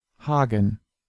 Hagen (German pronunciation: [ˈhaːɡn̩]
De-Hagen.ogg.mp3